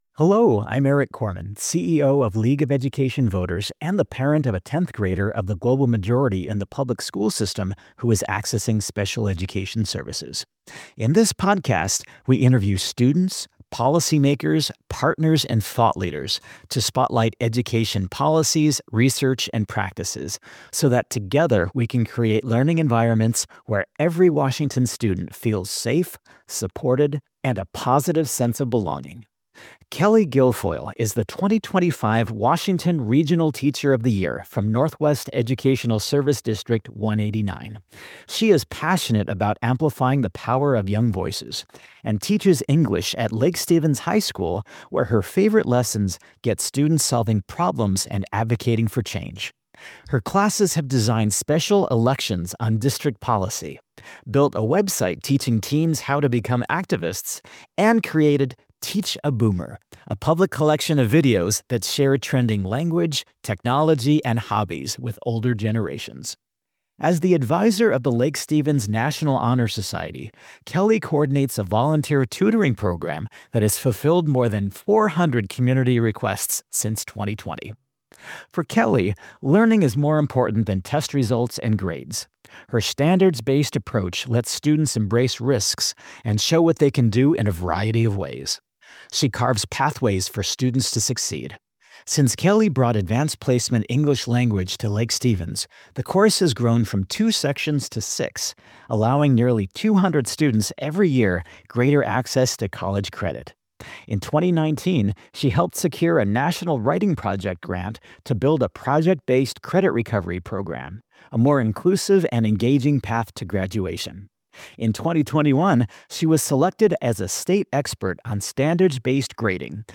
In our Putting Students First podcast, we interview students, policymakers, partners, and thought leaders to spotlight education policies, research, and practices so that together we can create learning environments where every Washington student feels safe, supported, and a positive sense of belonging, so that they can achieve academically and access the life they choose.